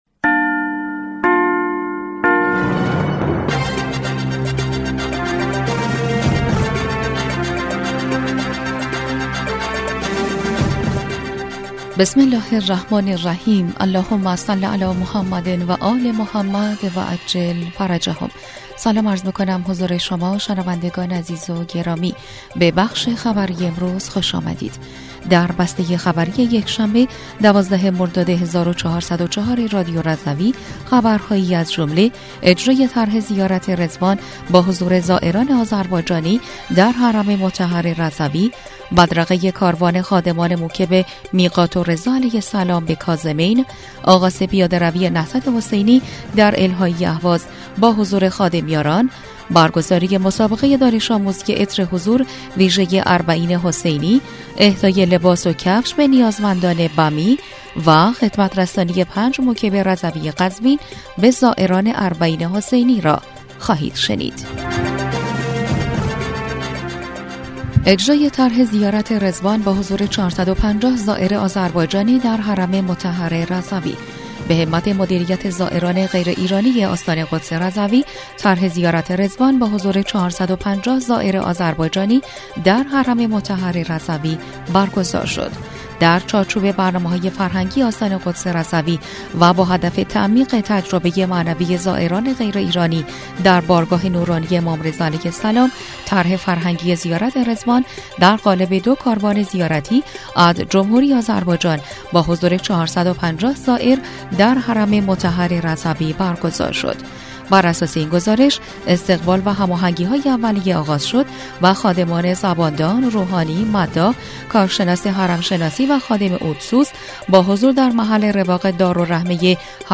بسته خبری ۱۲ مرداد ۱۴۰۴ رادیو رضوی/